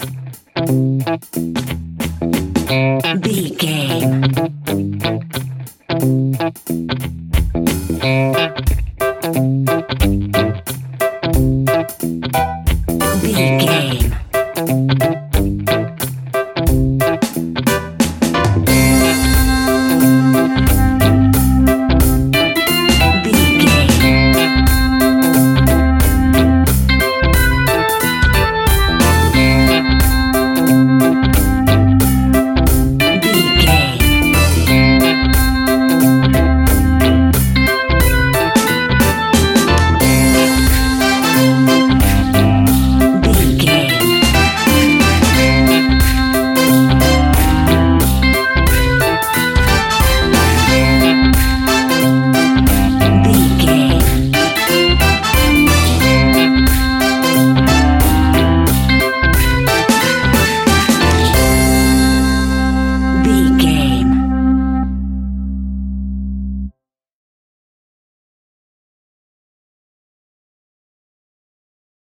Take me back to the old skool retro seventies reggae sounds!
Ionian/Major
reggae instrumentals
laid back
chilled
off beat
drums
skank guitar
hammond organ
percussion
horns